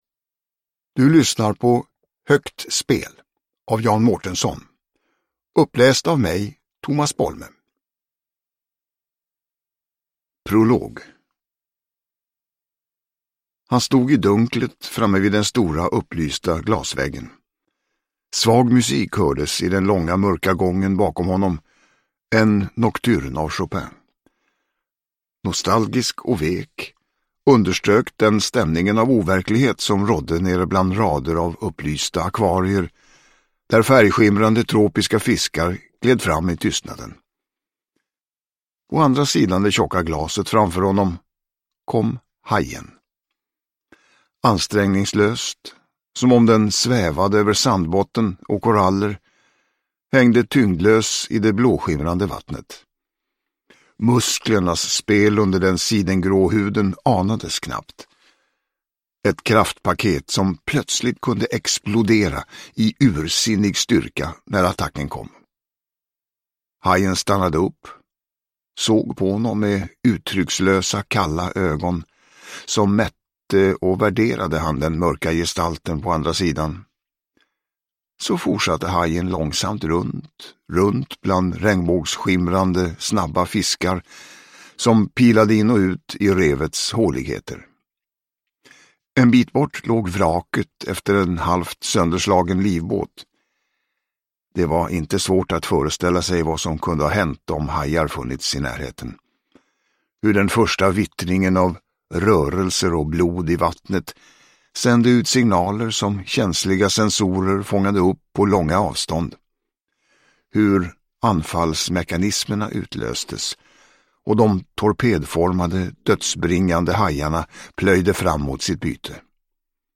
Uppläsare: Tomas Bolme
Ljudbok